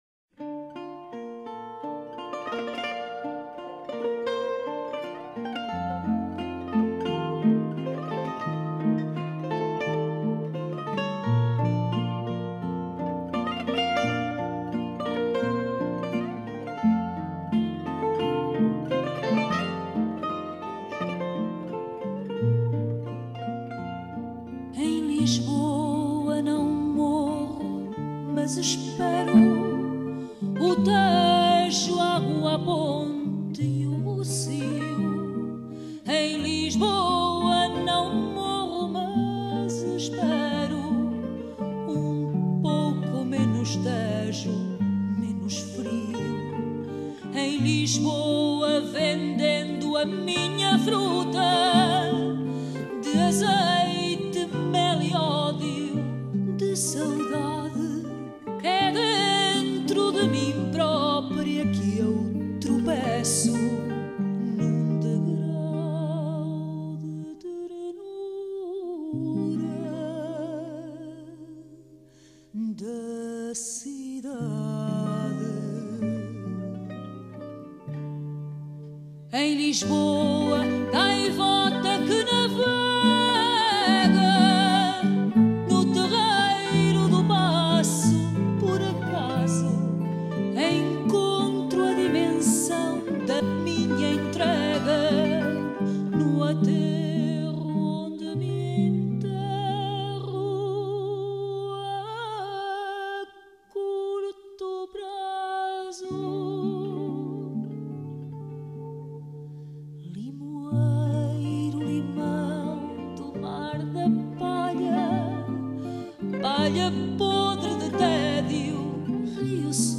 guitare portugaise
guitare classique
basse acoustique.